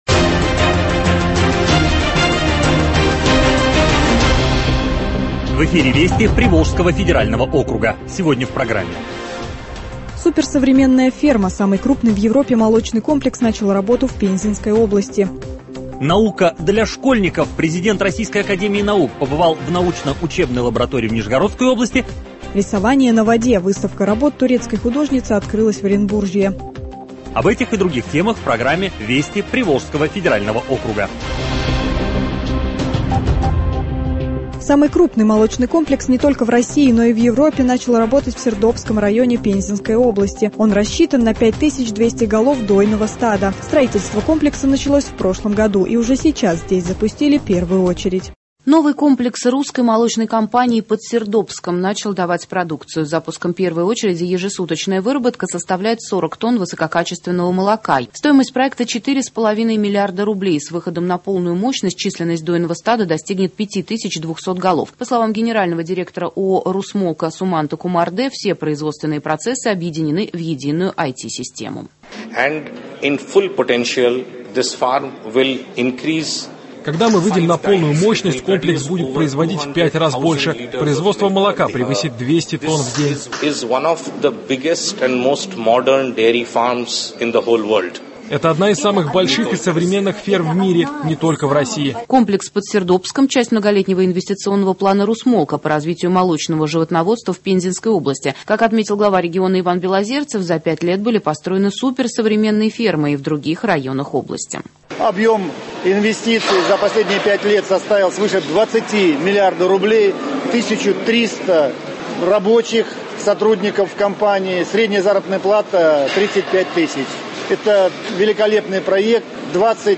Программа “Вести ПФО” в радиоверсии: репортажи с самых интересных событий Приволжского федерального округа.